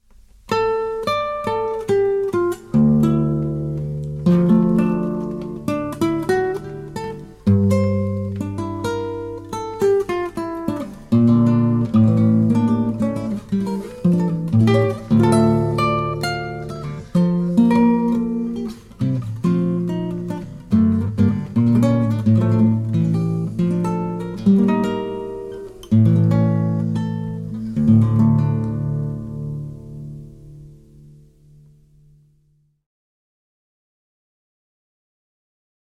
Sologitarren-Aufnahmen:
Solo-Klassikgitarre